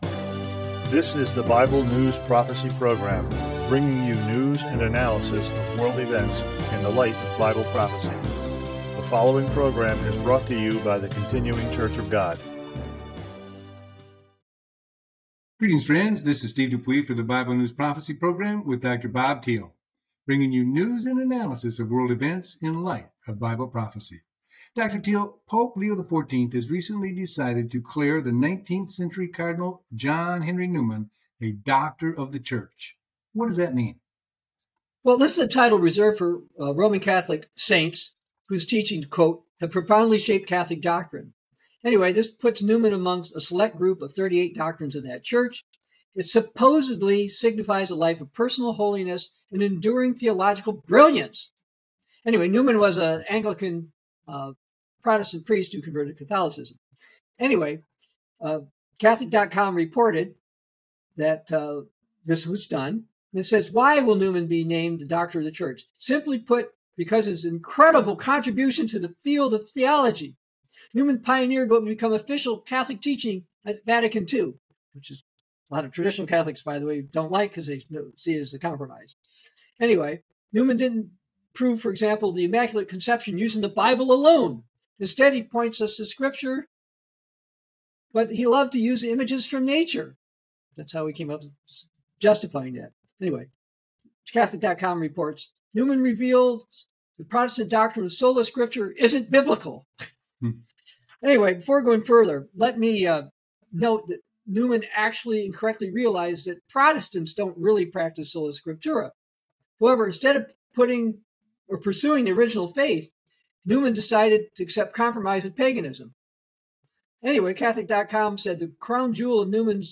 Bible News Prophecy Talk Show